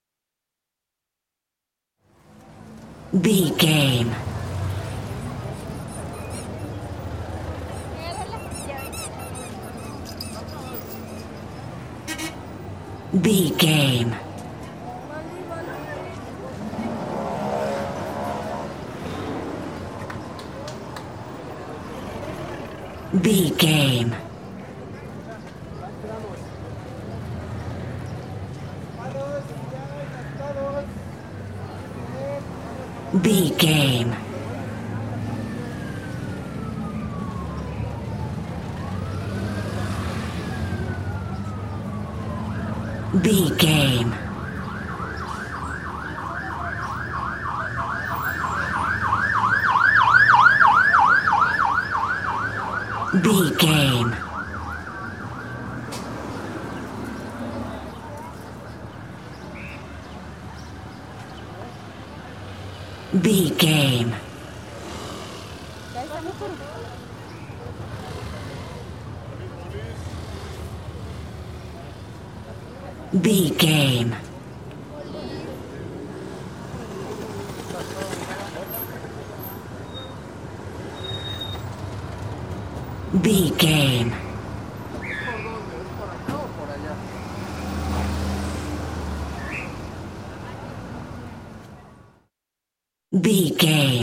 Mexico taxco street pedestrian vehicles
Sound Effects
urban
chaotic
ambience